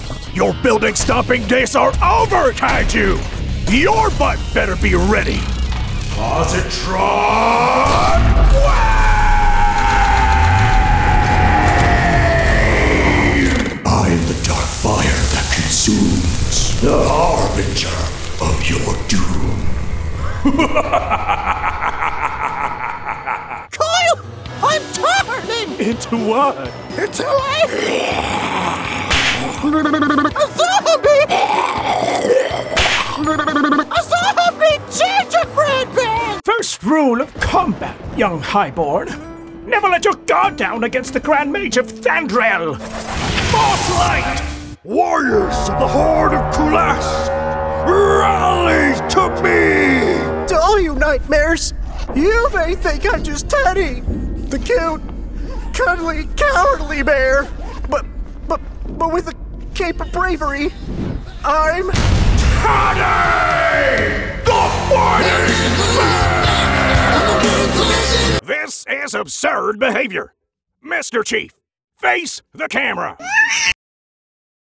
Friendly neighborhood voice actor...
Commercial Demo
southern, neutral